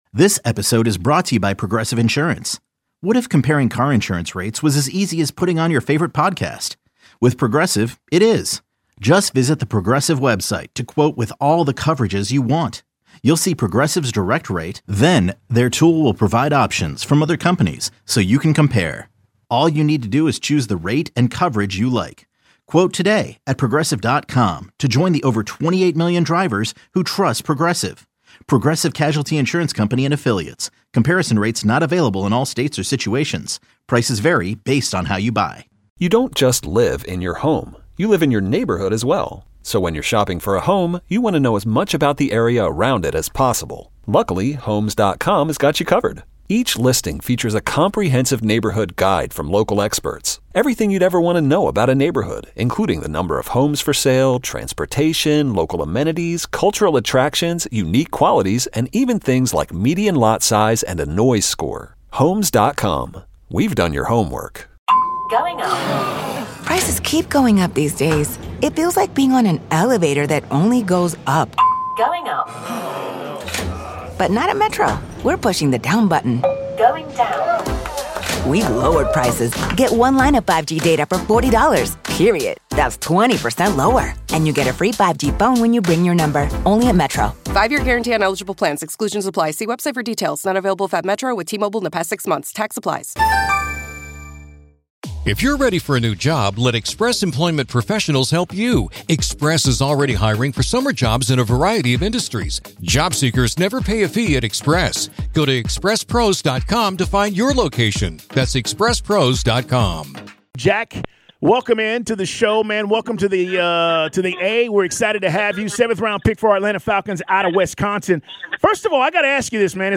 1 Best of 92-9 the Game Interviews: Lots of Falcons rookies stop by 1:20:20